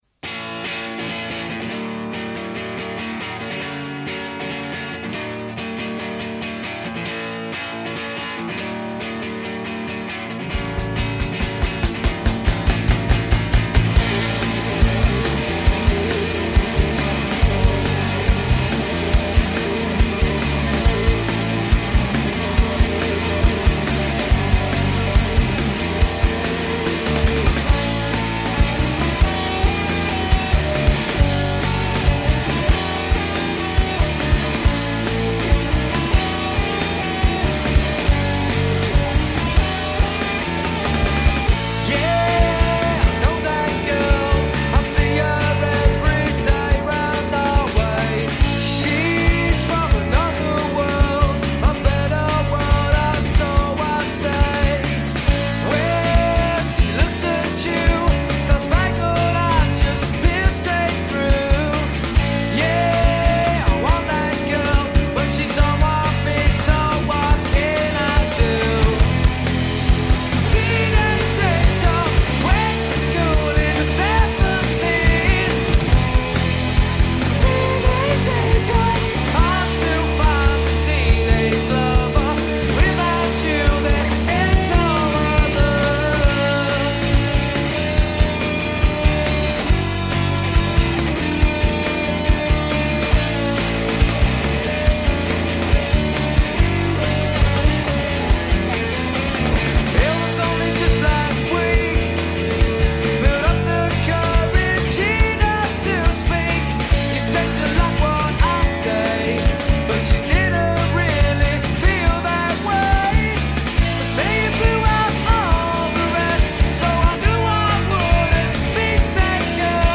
Jangle pop.